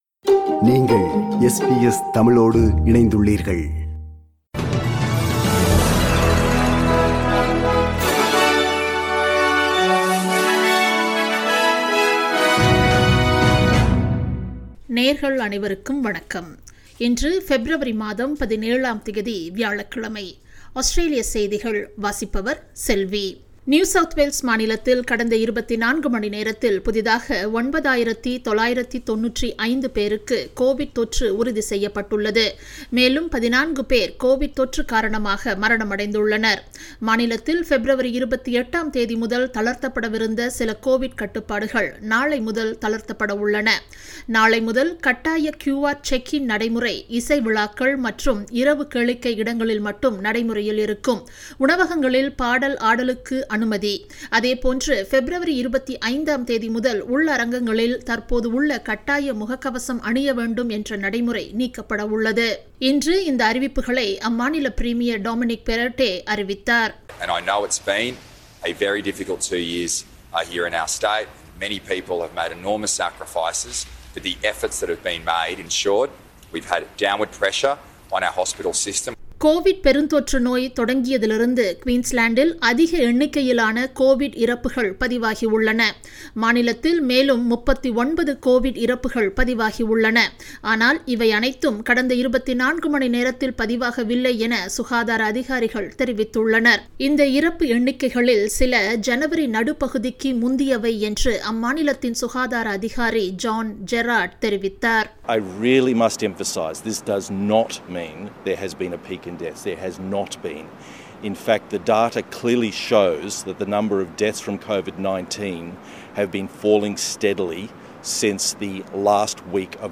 Australian news bulletin for Thursday 17 February 2022.